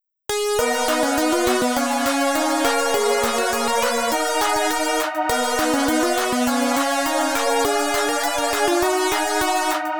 あまり強くマイナー感が強調されたわけでもないですが、
原曲に比べてポップさが減りかなり暗めになった気がしませんか？